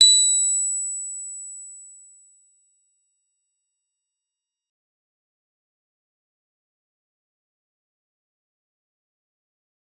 G_Musicbox-B7-f.wav